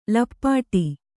♪ lappāṭi